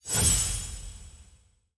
UI_BronzeAppear.ogg